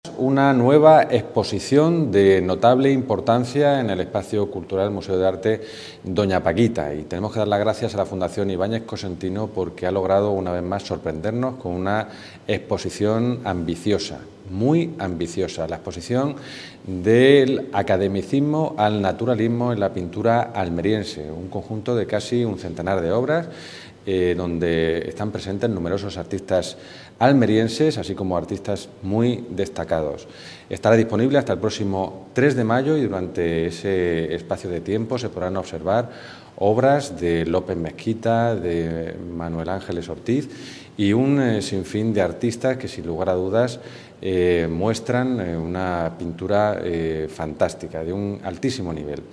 JOAQUIN-PEREZ-DE-LA-BLANCA-CONCEJAL-CULTURA-EXPOSICION-NATURALISMO-Y-ACADEMICISMO-PINTURA-ALMERIENSE.mp3